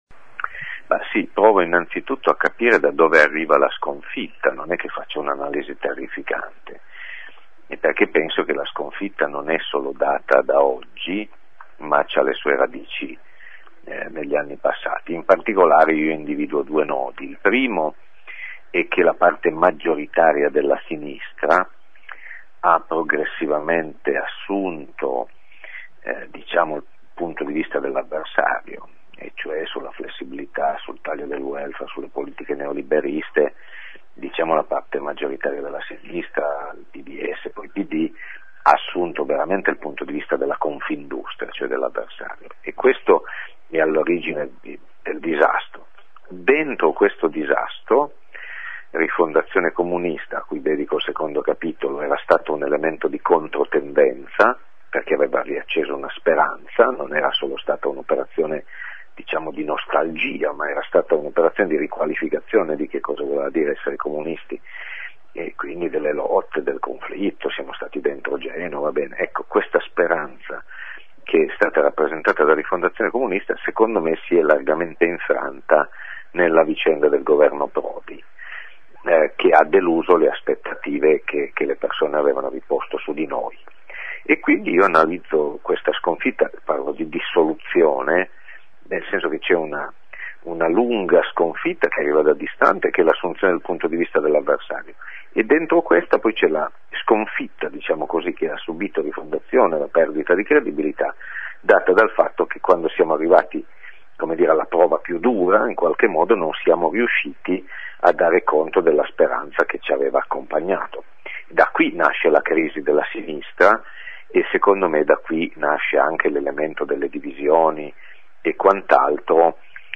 Tra questi, Paolo Ferrero, segretario di Rifondazione, che rivendica in Quel che il futuro dirà di noi, edito da Derive Approdi, la necessità di un movimento comunista. (ascolta l'intervista)